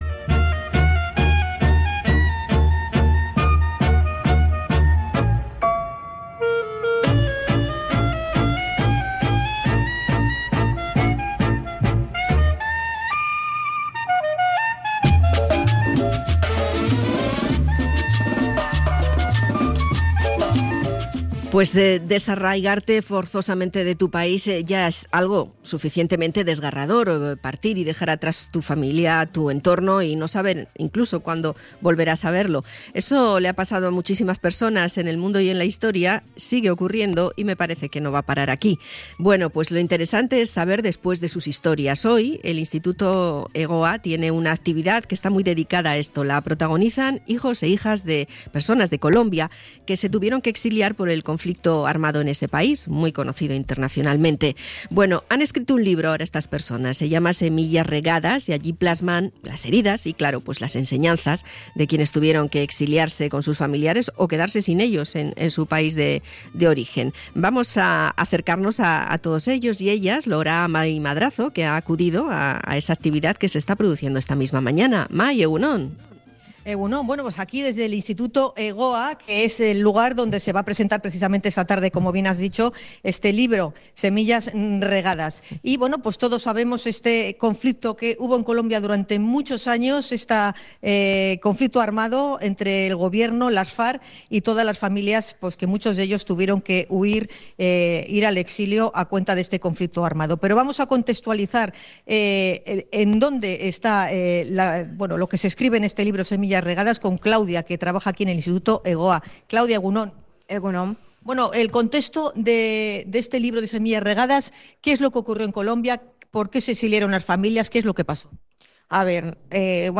Hijos del exilio colombiano relatan sus historias en Semillas Regadas